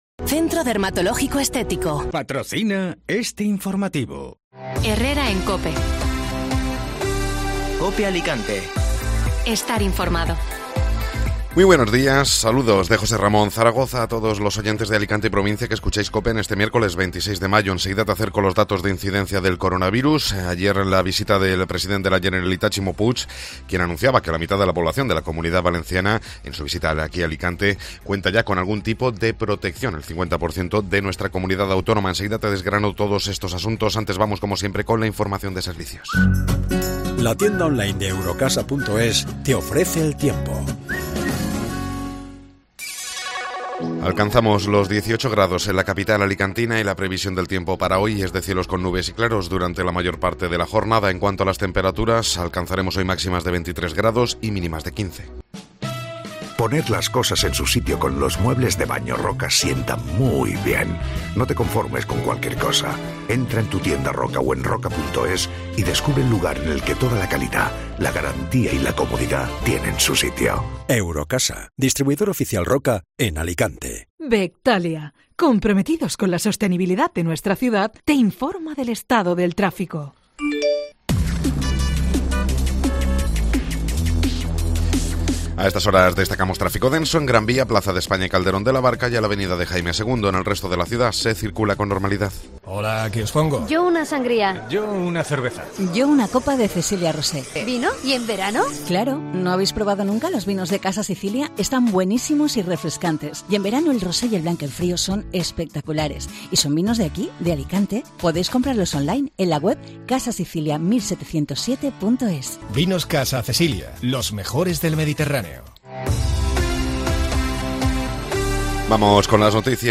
Informativo Matinal (Miércoles 26 de Mayo)